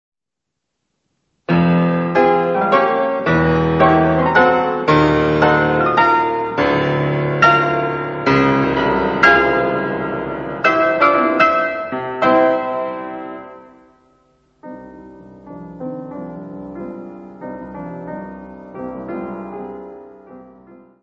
piano.
Área:  Música Clássica